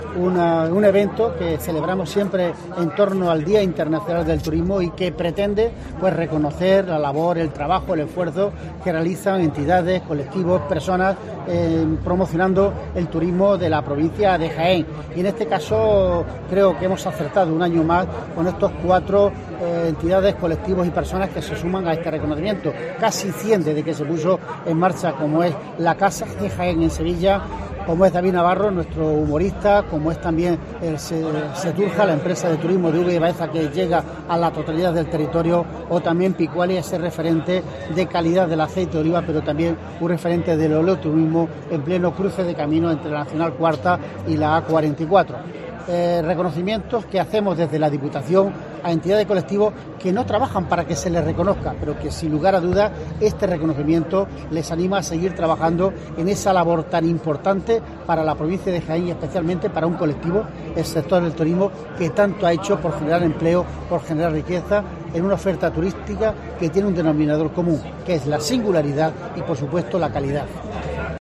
Francisco Reyes en los Premios Jaén Paraíso Interior entregados en Sabiote